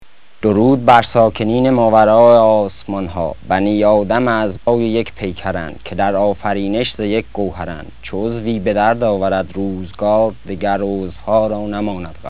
یکی از مشهورترین و طولانی‌ترین صداهای ضبط شده، شعری مشهور از سعدی است که توسط یک ایرانی خوانده شد.